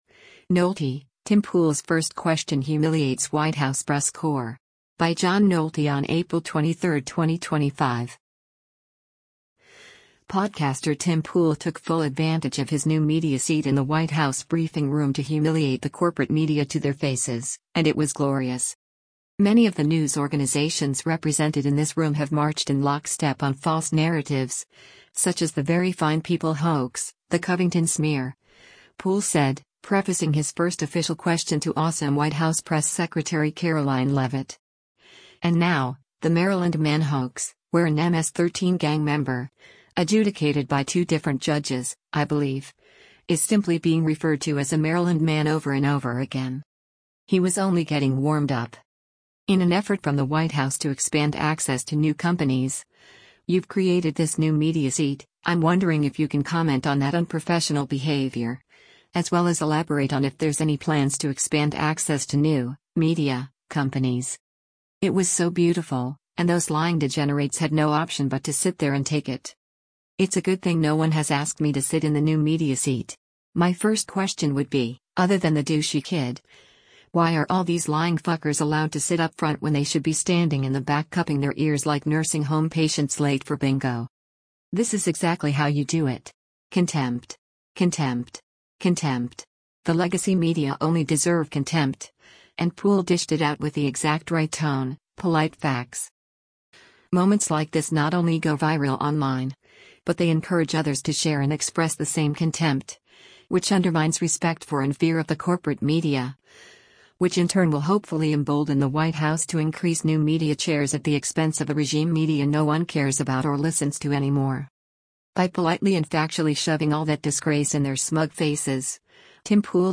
Podcaster Tim Pool speaks during a White House press briefing on April 22, 2025.
The legacy media only deserve contempt, and Pool dished it out with the exact right tone: polite facts.